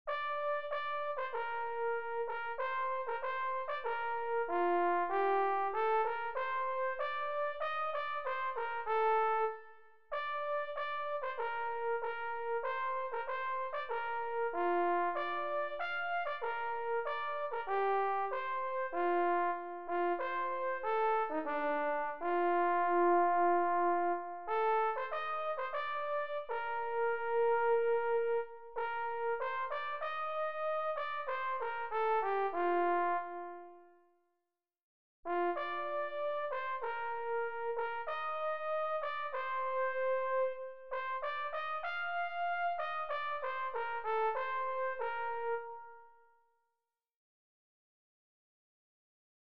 Chant traditionnel